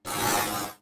Futuristic Weapons Sound Effects – Misc 12 – Free Music Download For Creators
Futuristic_Weapons_Sound_Effects_-_misc_12.mp3